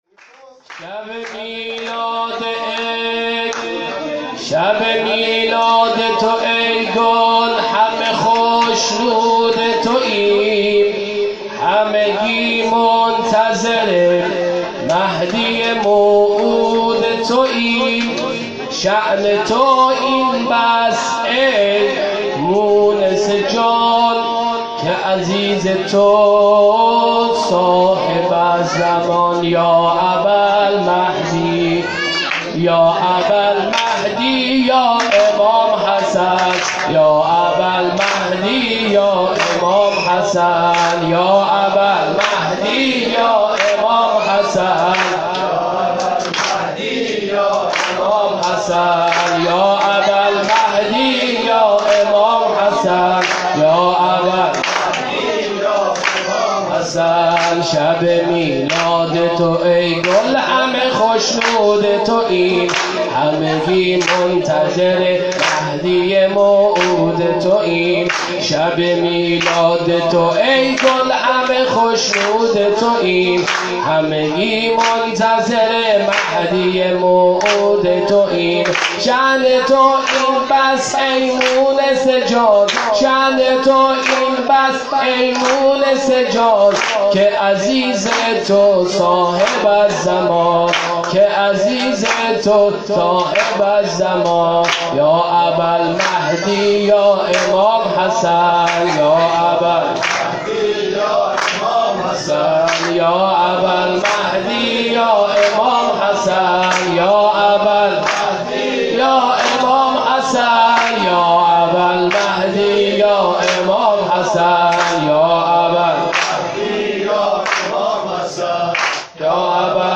ولادت امام حسن عسکری{ع}96
سرود